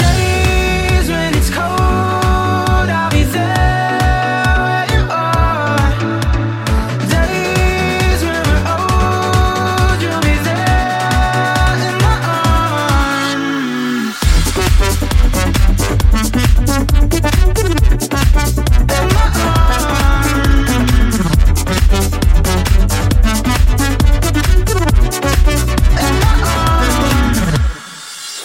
Genere: pop, deep, club,, remix